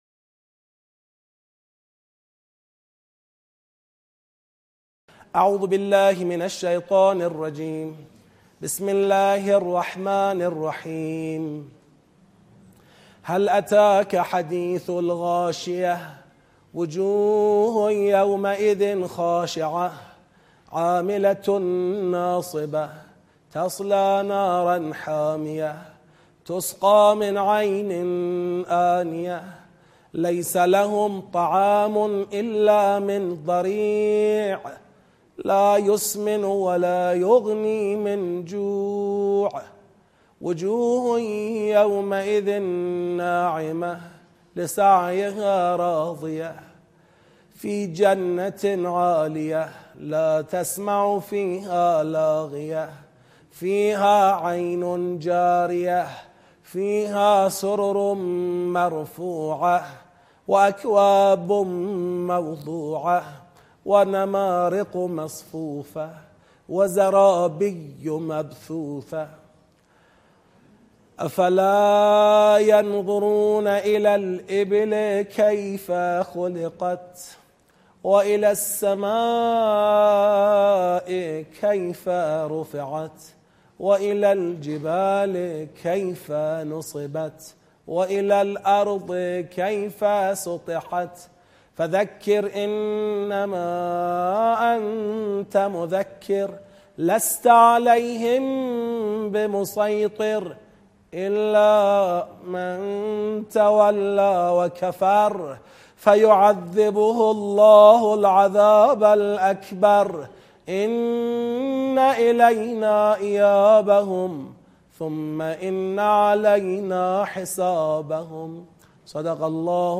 در این ویدئو قرائت تدبری سوره غاشیه را مشاهده میکنید. این قرائت مربوط به دوره آموزش سطح یک تدبر در قرآن کریم است که تابستان و پاییز ۹۸ در شهرک شهید محلاتی برگزار شده است.
17-1-قرائت-تدبری-سوره-غاشیه.mp3